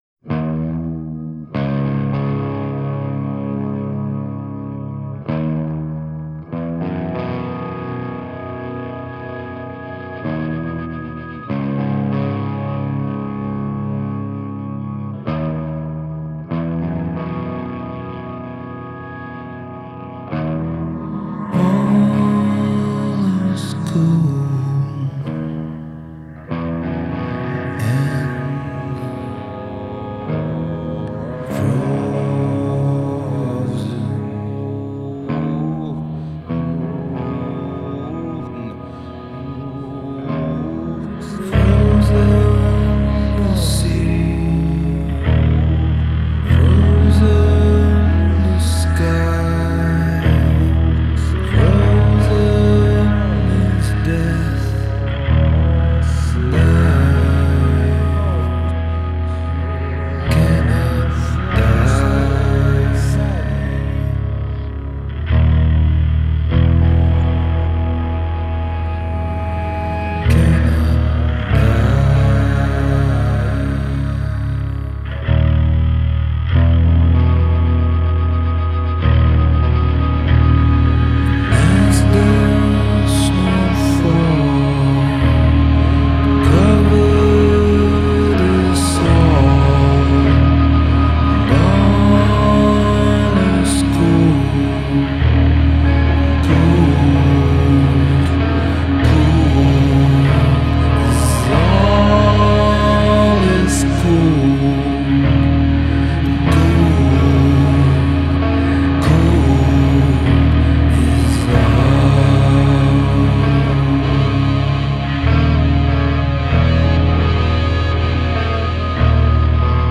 واقعا ریف گیتار هاش و ووکالش ترسناکه که کاملا به دووم میخوره
Doom metal